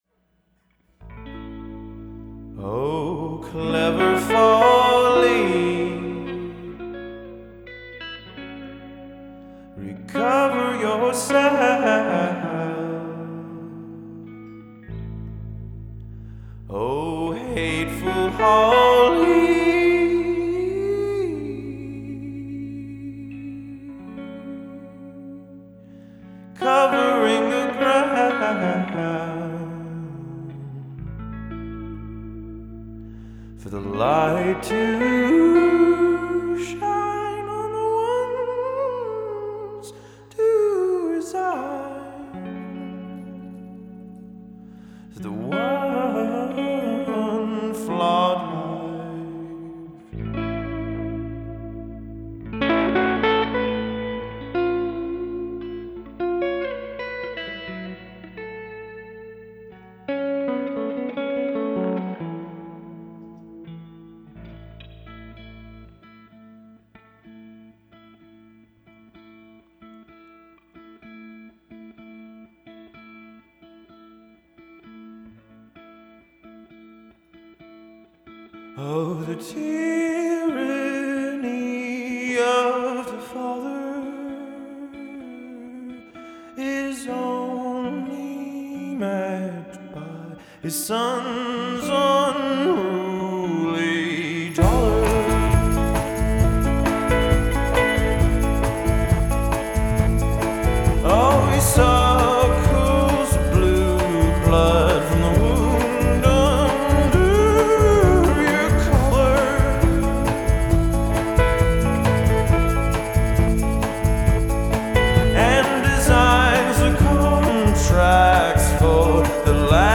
vocal, organ, guitar
viola, vocal
bass guitar
piano/wurlitzer